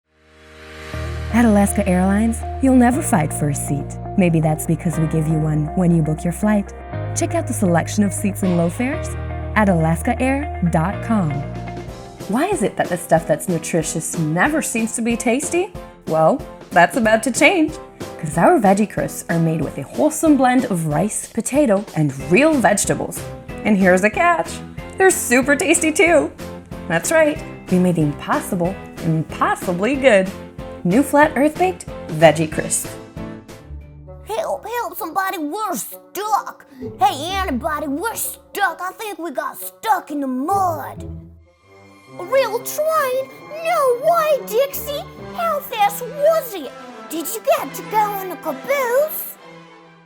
Démo voix anglais